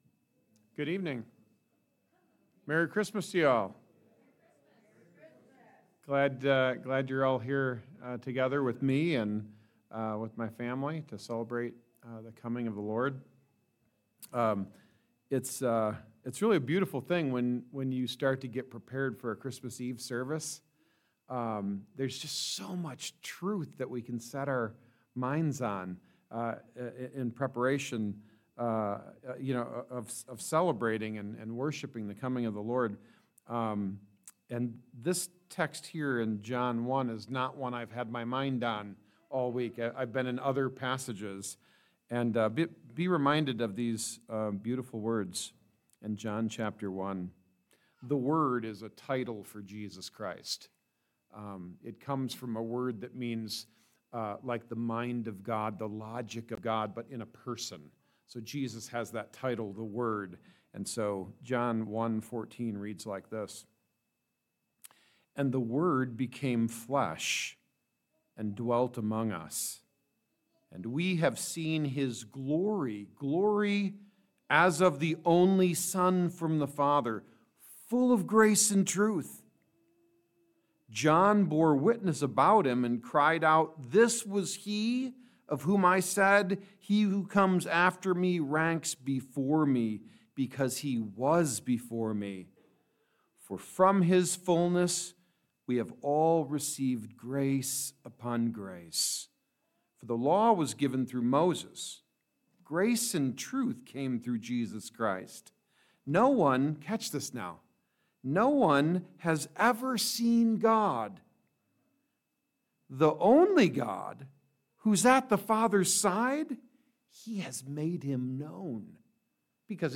Service Type: Christmas Eve